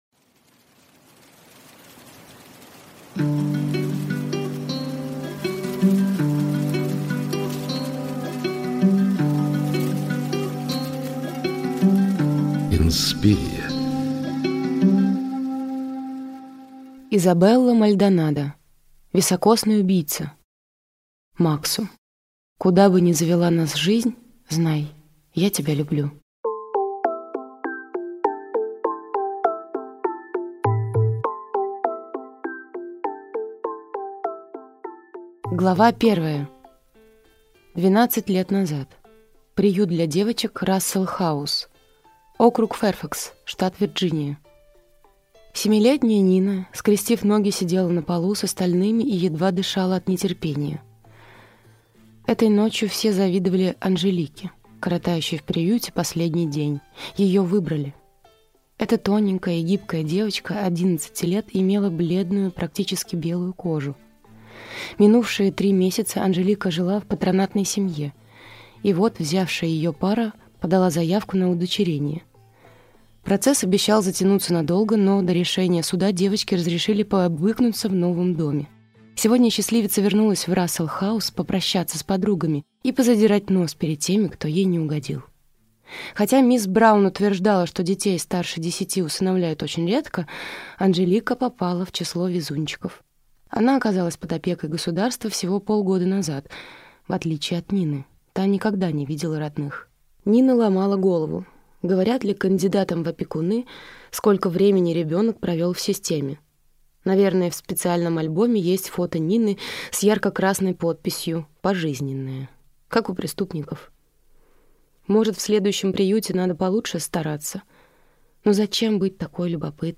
Аудиокнига Високосный убийца | Библиотека аудиокниг
Прослушать и бесплатно скачать фрагмент аудиокниги